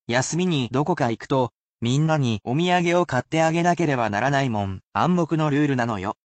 The sentences, however, would be a bit much to memorise and are meant to be used as examples rather than reused in their entirety, so these are read aloud at normal speed.
[casual speech]